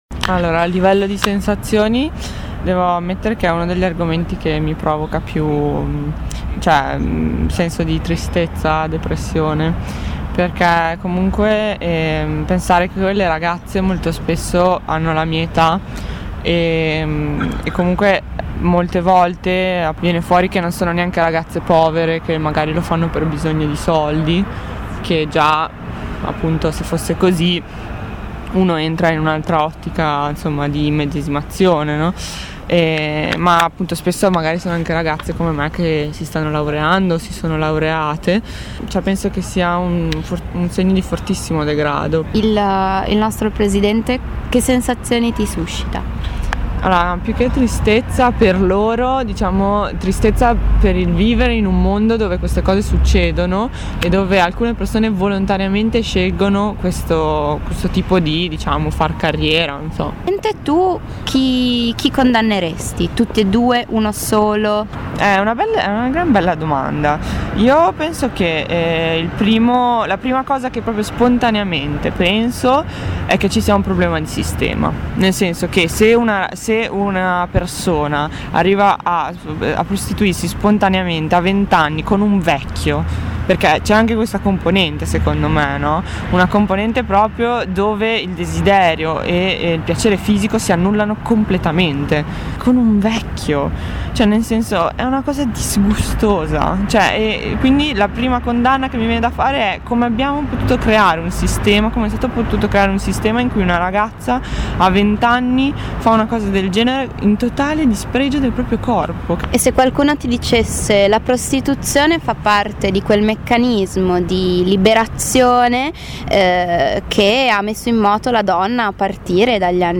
Ascolta le riflessioni di due giovani ventenni, raccolte dalle redattrici di College playlist: